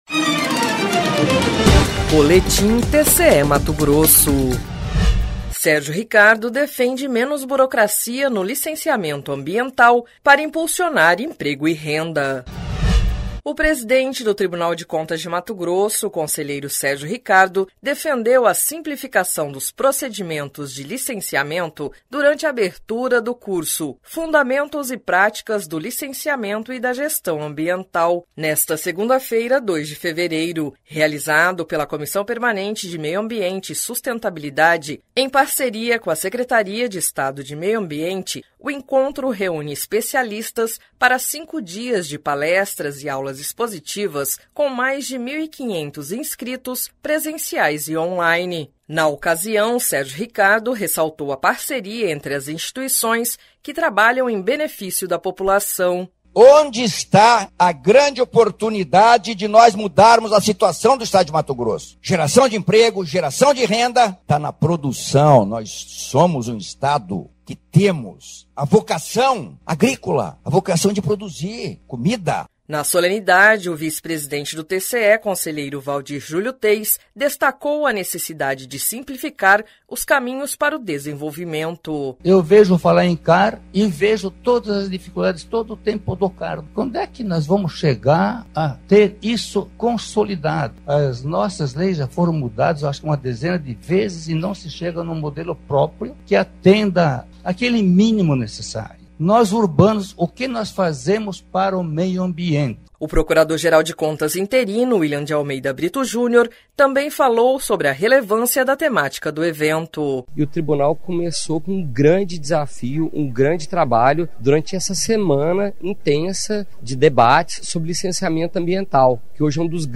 Sonora: Sérgio Ricardo – conselheiro-presidente do TCE-MT
Sonora: Waldir Júlio Teis – conselheiro vice-presidente do TCE-MT
Sonora: William de Almeida Brito Júnior - procurador-geral de Contas interino